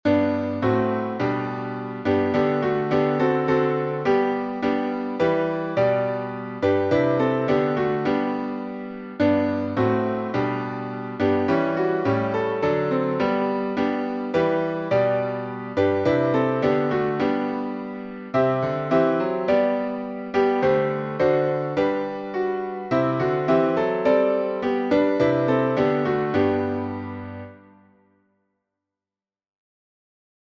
#1024: Once in Royal David's City — G Major | Mobile Hymns
Key signature: G major (1 sharp) Time signature: 4/4